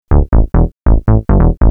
Techno / Bass / SNTHBASS145_TEKNO_140_A_SC2.wav